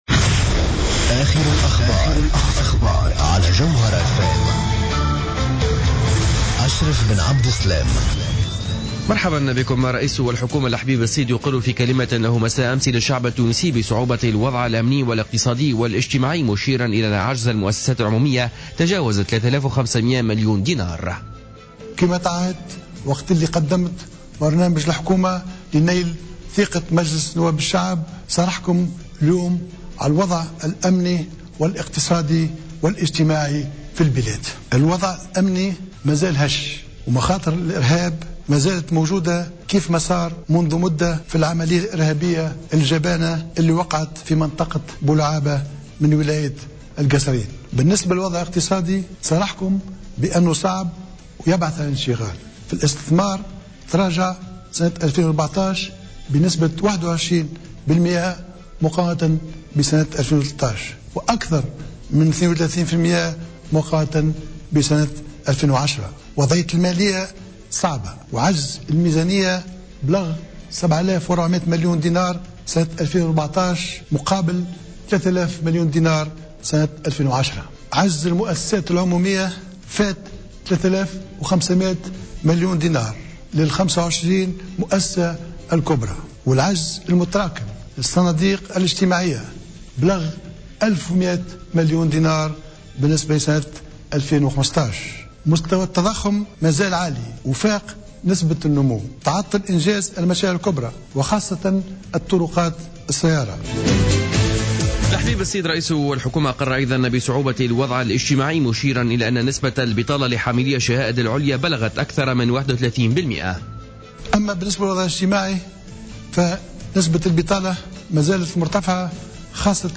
نشرة أخبار منتصف الليل ليوم الثلاثاء 17 مارس 2015